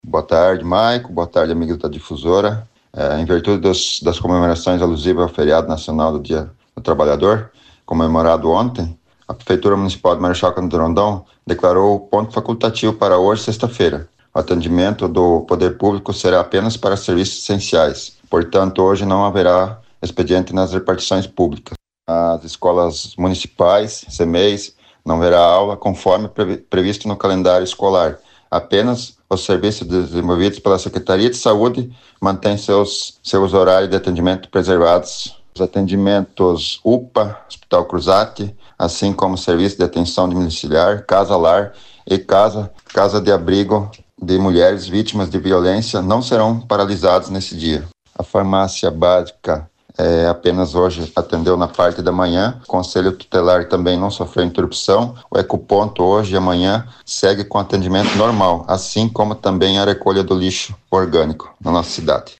Ouça entrevista com secretário de gestão de governo de Marechal Rondon, Gilmar Dattein………OUÇA ÁUDIO